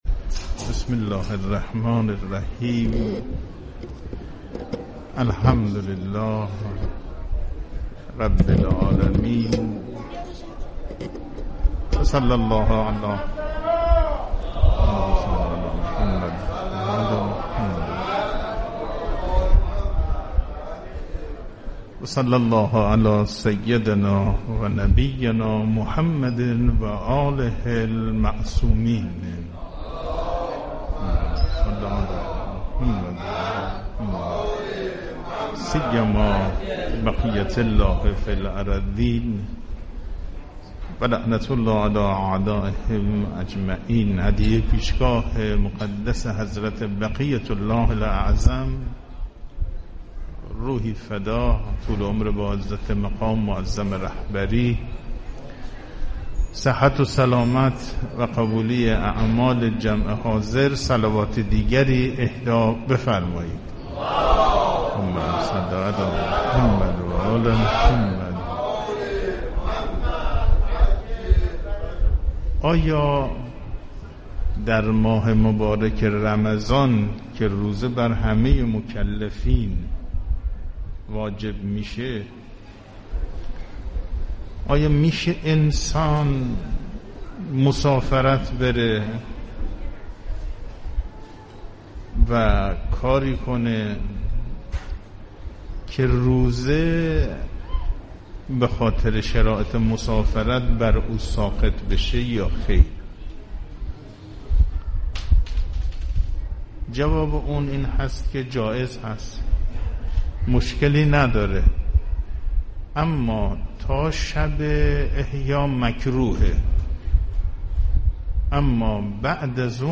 صوت| درس اخلاق حجت الاسلام والمسلمین صفایی بوشهری در مسجد امام حسن مجتبی(ع)
حوزه/ سلسله جلسات درس اخلاق حجت الاسلام والمسلمین صفایی بوشهری نماینده ولی فقیه در استان بوشهر در مسجد امام حسن مجتبی علیه السلام بوشهر در ماه مبارک رمضان در حال برگزاری است.